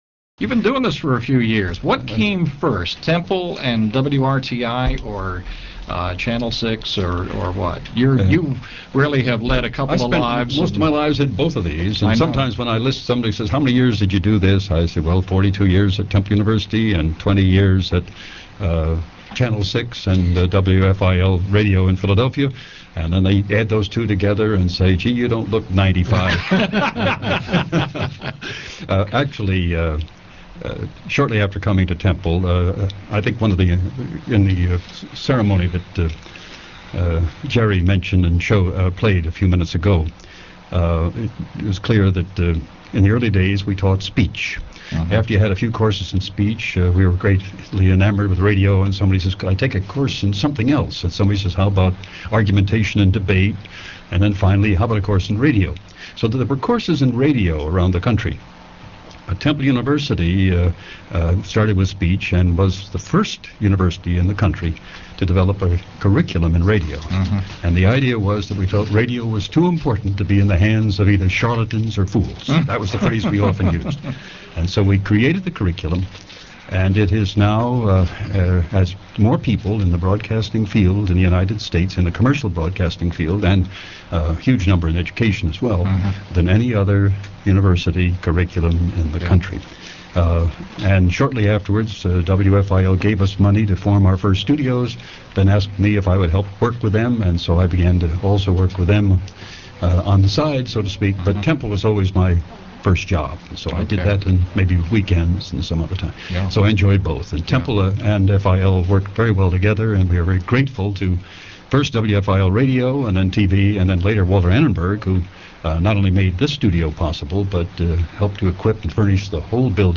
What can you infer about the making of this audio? Here are excerpts from that broadcast: